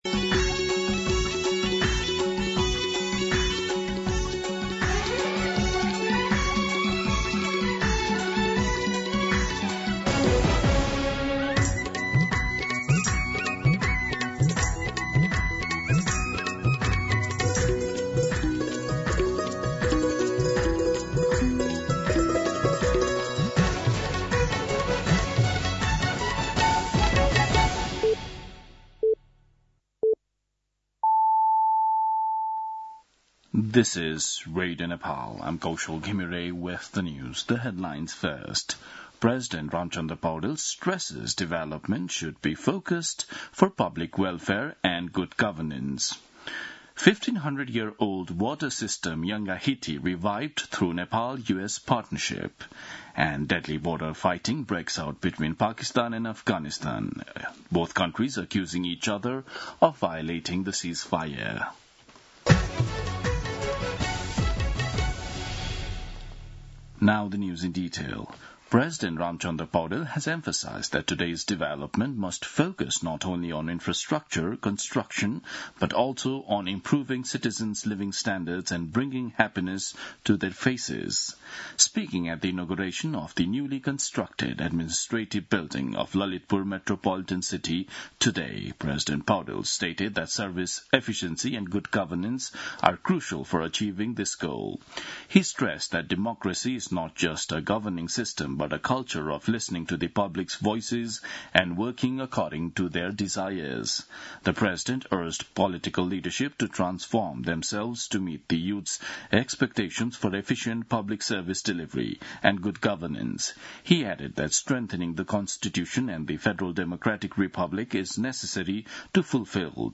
दिउँसो २ बजेको अङ्ग्रेजी समाचार : २० मंसिर , २०८२
2-pm-English-News-1.mp3